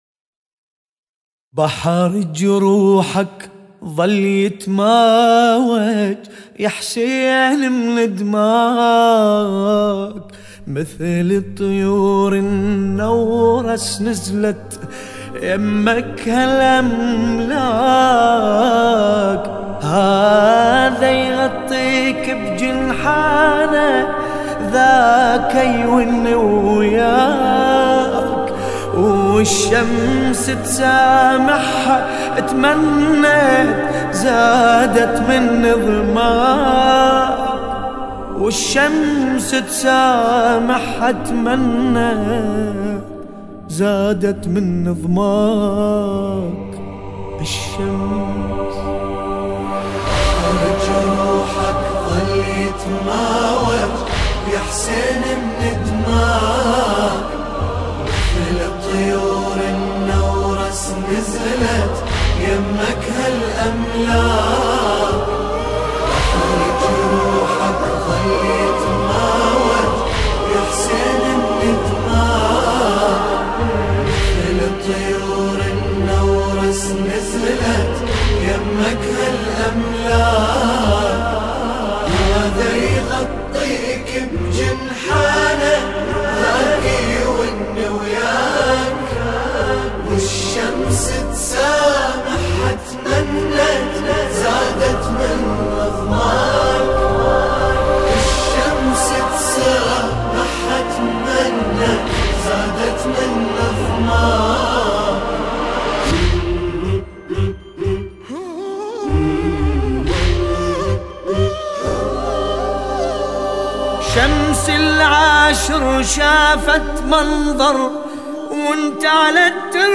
قصيدة